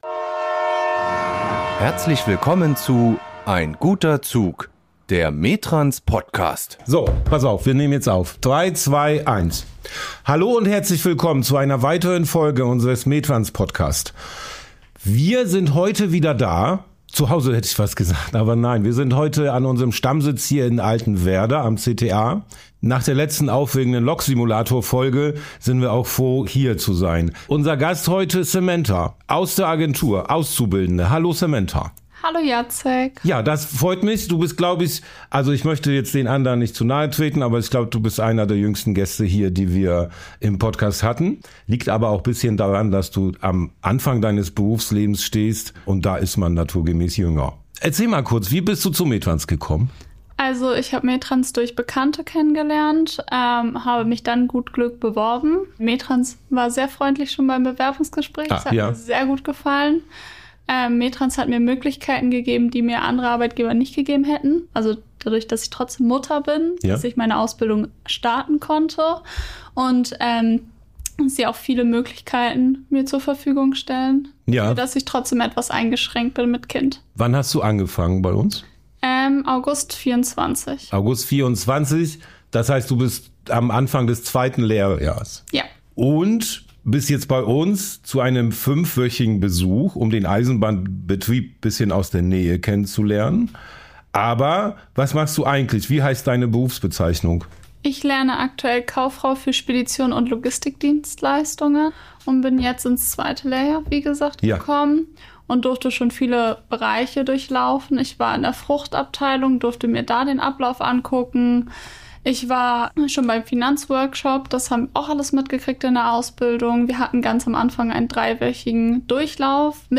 Rollenwechsel im Podcaststudio ~ Ein Guter Zug - Der METRANS Podcast